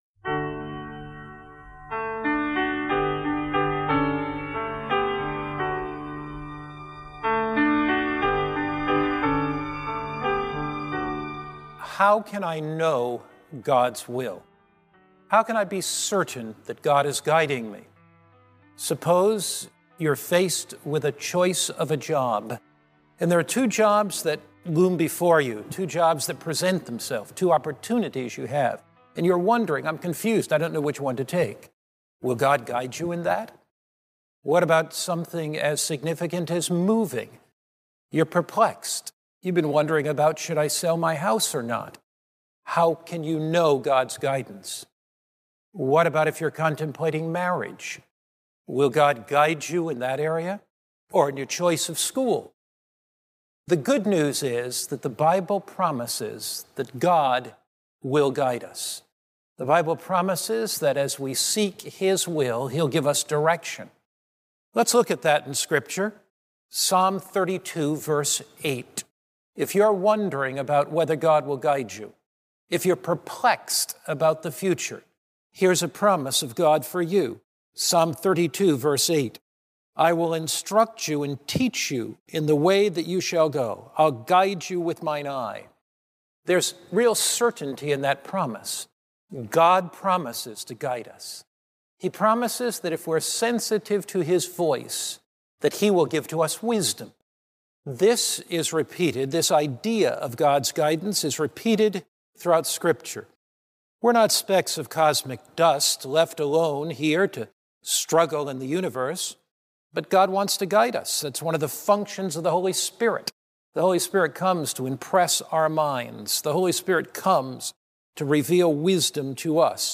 This sermon explores how scripture, pure motives, wise counsel, and faith all play a role in making godly decisions. Discover how to trust His guidance, recognize His providence, and move forward with confidence in His plan!